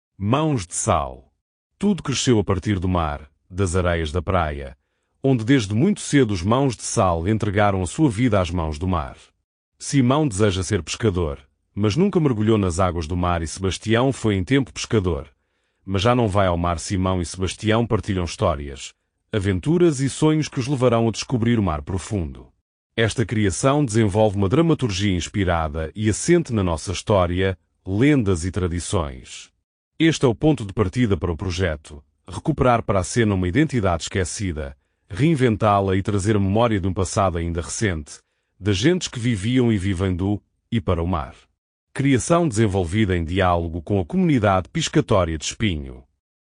este áudio guia possui 13 faixas e duração de 00:19:43, num total de 13.9 Mb